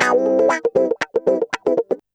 Index of /90_sSampleCDs/USB Soundscan vol.04 - Electric & Acoustic Guitar Loops [AKAI] 1CD/Partition C/04-120GROWAH